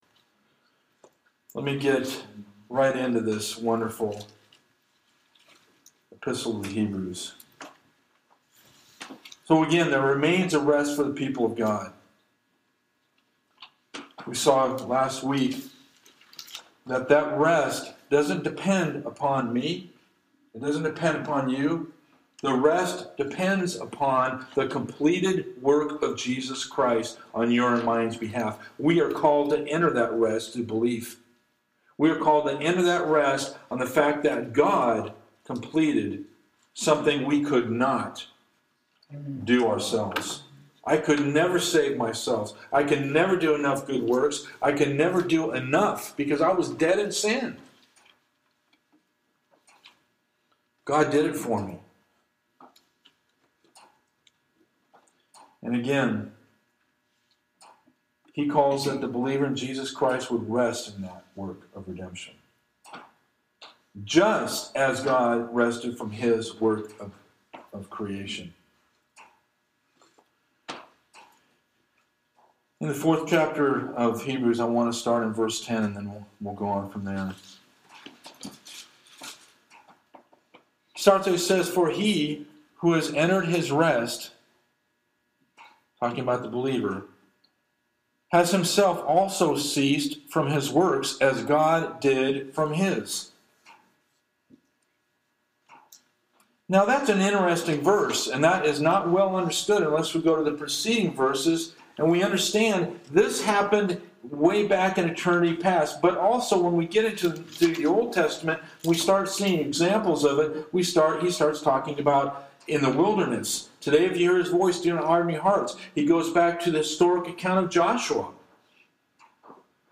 Genre: Sermon.